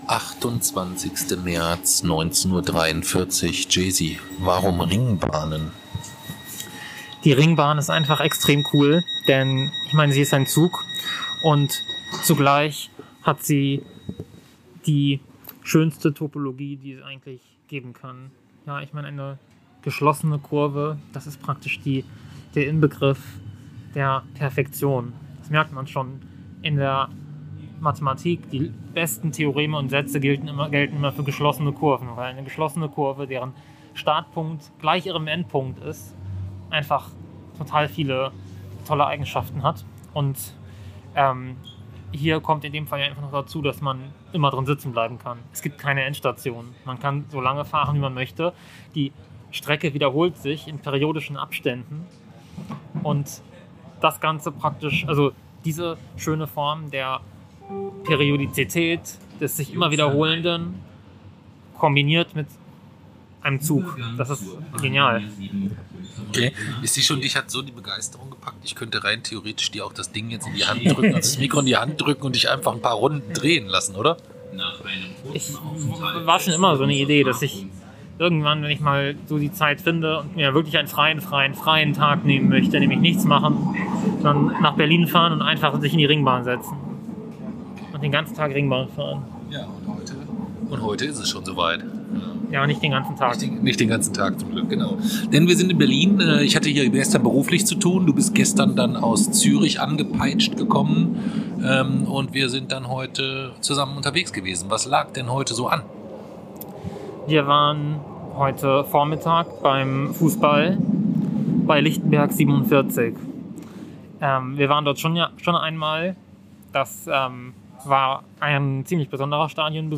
Podcast aus der Berliner Ringbahn ~ Radiorebell-Podcast der Wochenendrebellen Podcast
Wir waren am Wochenende in Berlin und ich mein Plan für den Abend sah sowohl Podcasten als auch eine Runde Ringbahn Fahren vor. Da die Zeit für beides jedoch knapp würde, lag die Lösung auf der Hand: eine Podcastfolge einfach direkt aus der Ringbahn!